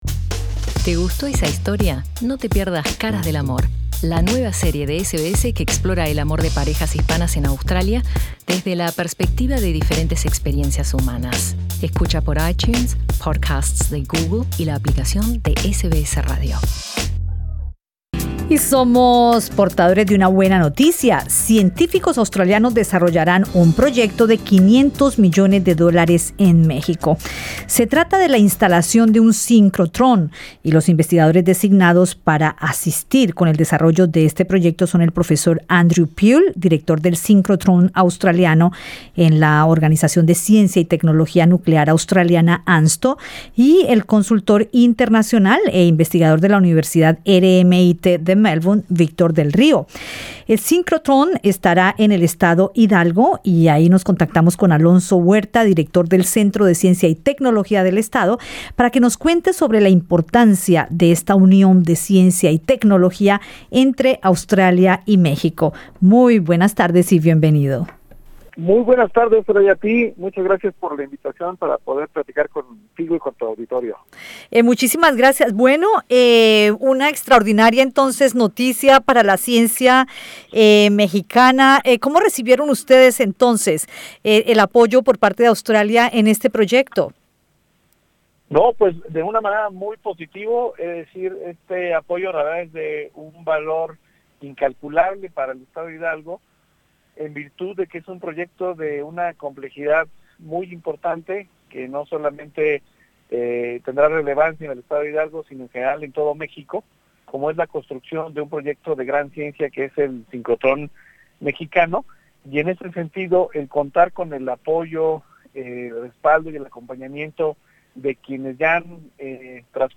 contaron en entrevista con Radio SBS